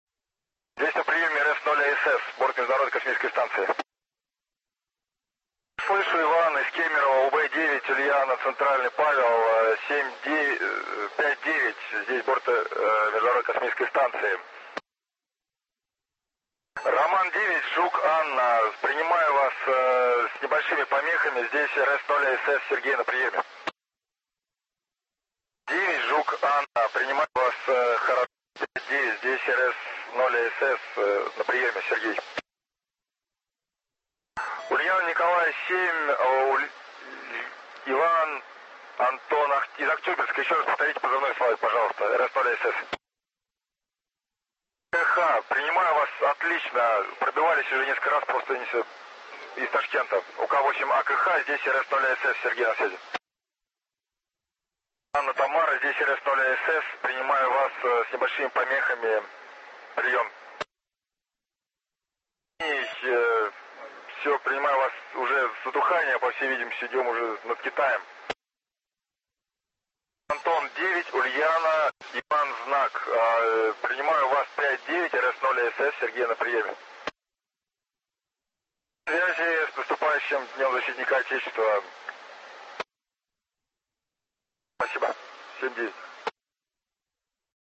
Космонавт с радиолюбителями
s_radiolubitelami_20.mp3